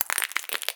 High Quality Footsteps / Glass Enhancement
STEPS Glass, Walk 06.wav